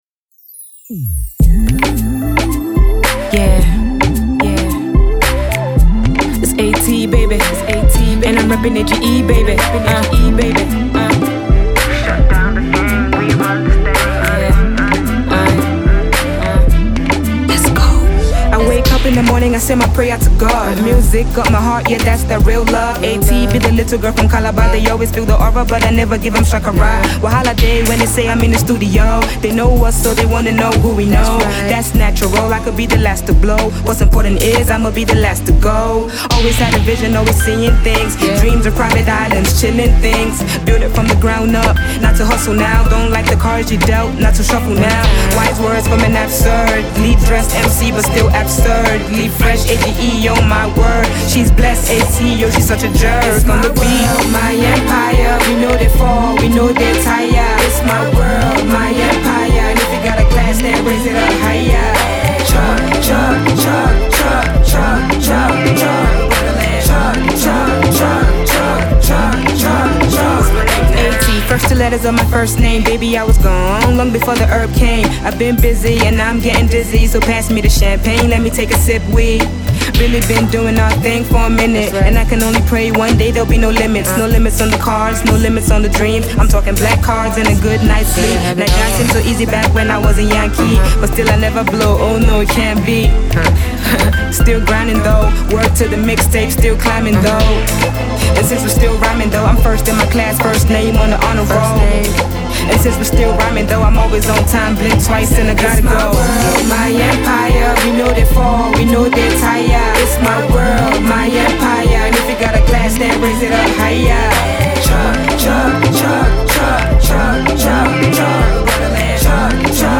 vocal prowess and wordplay
femcee